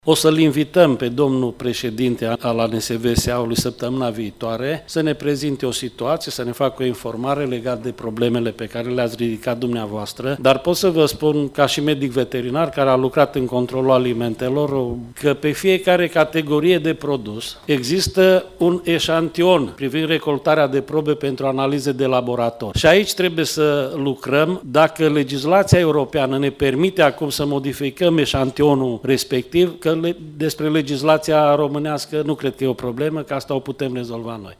Președintele Comisiei de Agricultură din Camera Deputaților, Ionel Ciunt, a anunțat că așteaptă explicații din partea președintelui ANSVSA în privința controalelor la vamă:
03feb-15-Ciunt-seful-ANSVSA-in-comisie.mp3